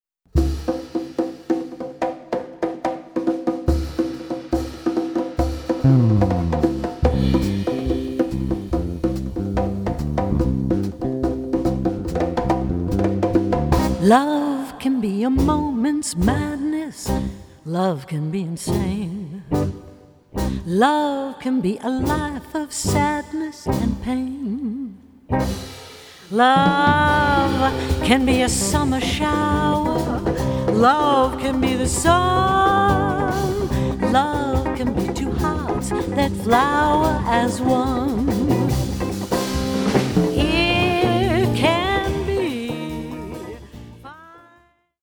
vocals
bass
guitar
drums 1.